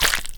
Minecraft Version Minecraft Version latest Latest Release | Latest Snapshot latest / assets / minecraft / sounds / block / frogspawn / hatch1.ogg Compare With Compare With Latest Release | Latest Snapshot
hatch1.ogg